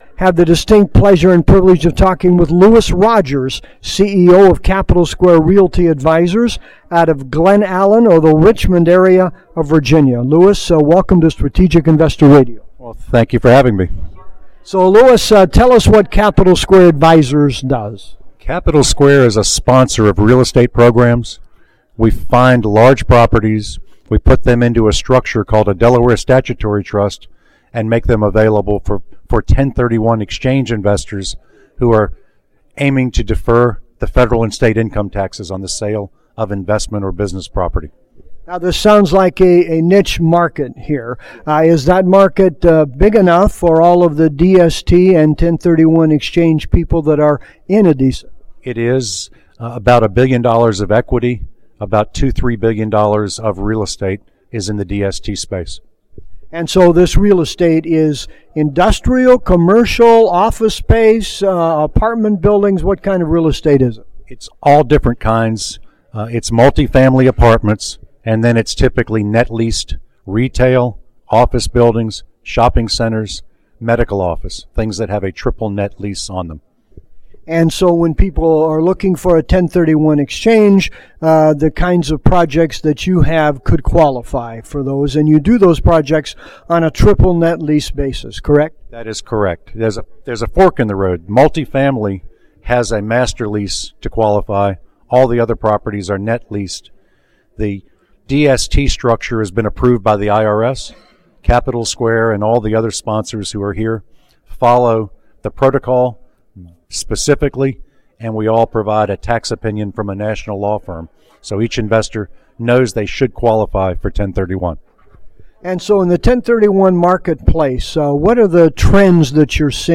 This interview was done at the ADISA Conference in San Diego.